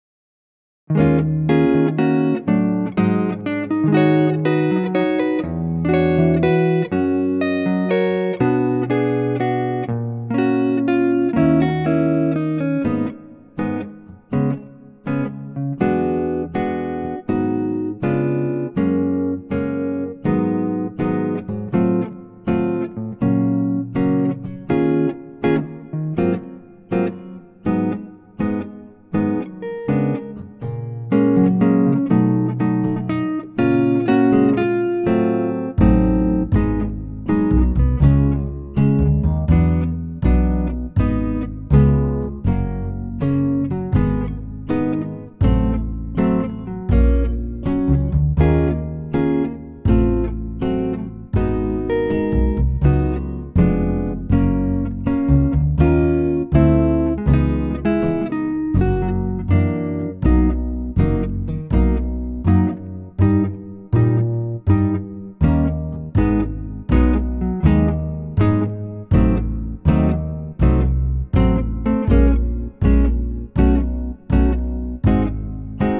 PIANO REMOVED!
key - Gb - vocal range - Gb to Ab